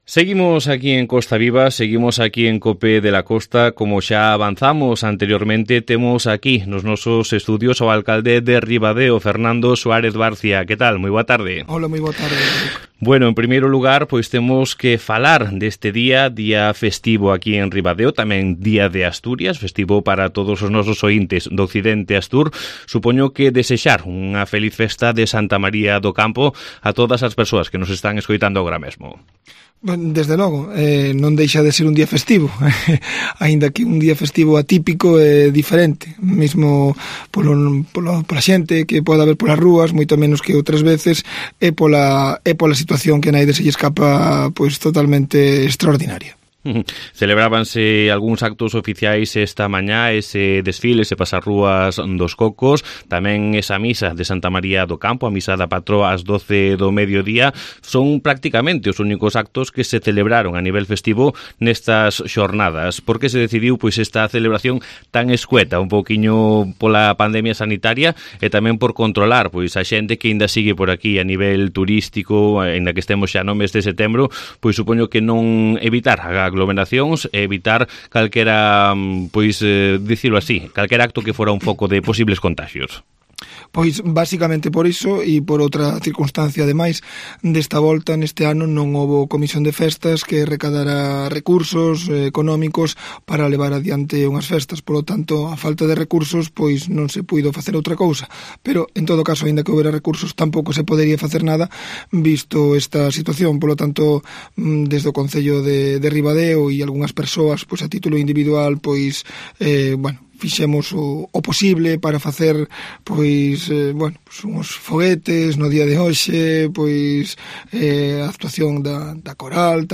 AUDIO: Fernando Suárez Barcia visitó los estudios de COPE de la Costa en esta peculiar jornada festiva